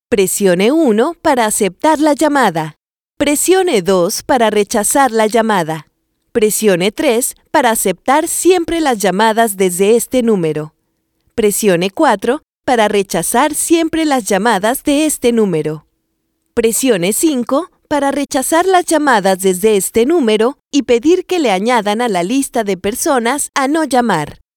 Professional Voice Prompts in Mexican Spanish
Features included in the prompt pack: Native Mexican Spanish voice artist with a professional business voice.